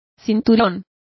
Complete with pronunciation of the translation of girdle.